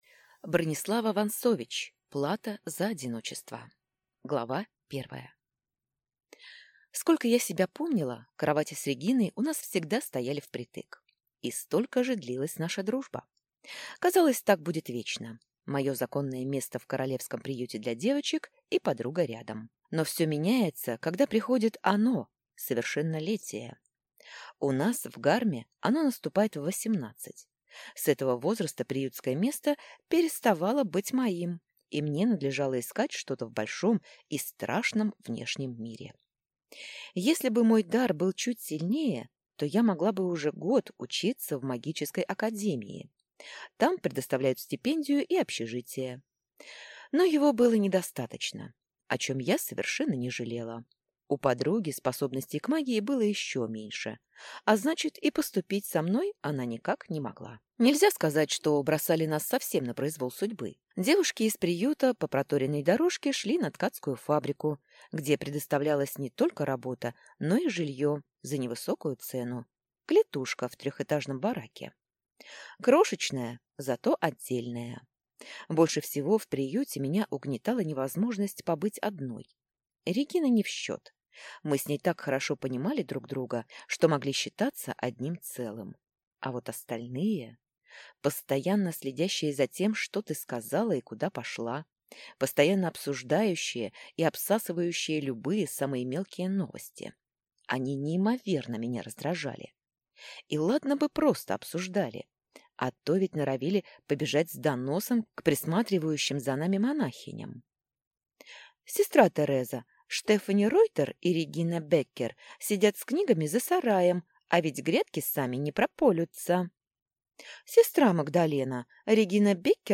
Аудиокнига Плата за одиночество - купить, скачать и слушать онлайн | КнигоПоиск